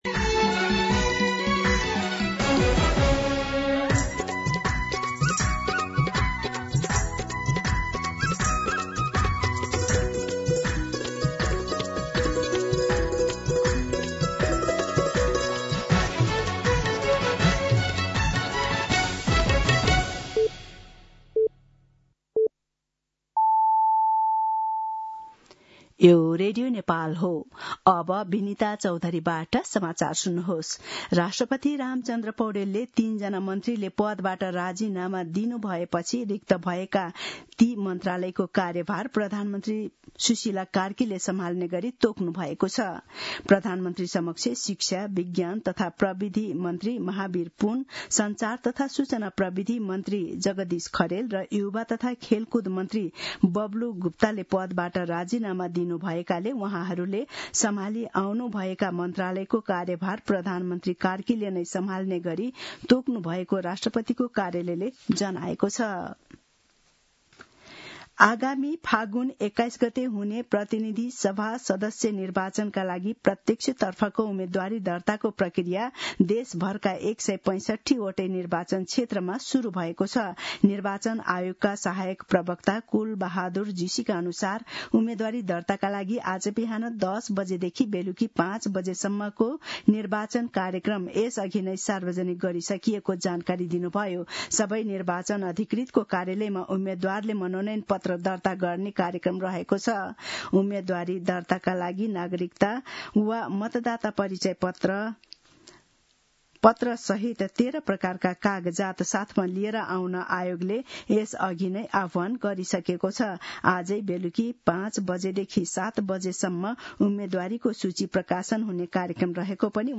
मध्यान्ह १२ बजेको नेपाली समाचार : ६ माघ , २०८२
12pm-News-10-6.mp3